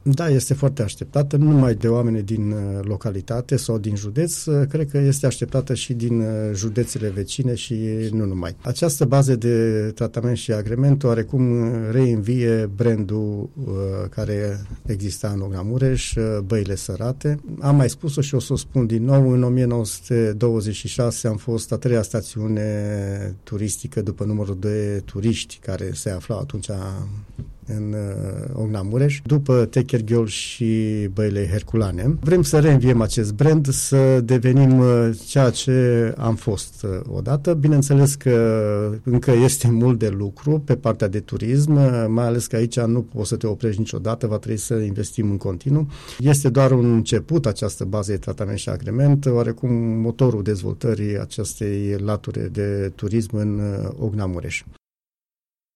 Băile Sărate din Ocna Mureș ar putea fi inaugurate la sfârșitul lunii martie, a anunțat la Unirea FM primarul orașului Ocna Mureș, Silviu Vințeler.